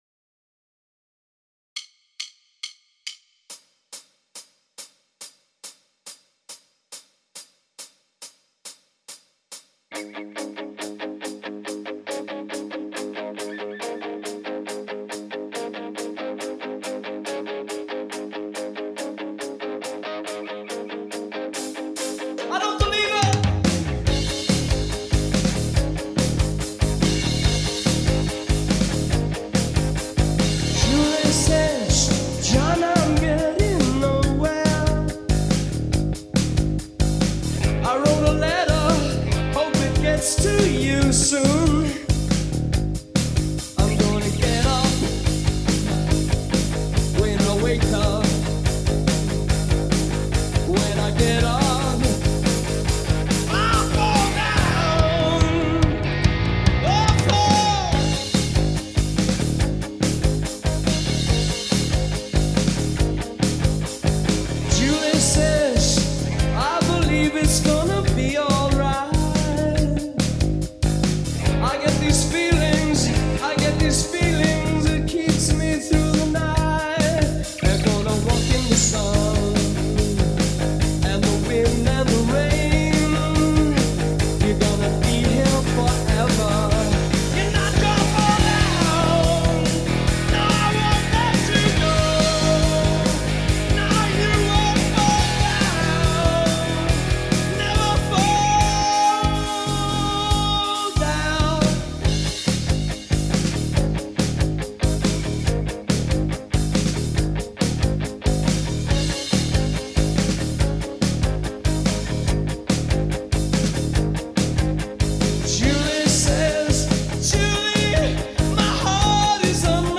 BPM : 141
Tuning : Eb
With second guitar
Without Piano
With vocals
Based on the 1982 Hammersmith Palais live version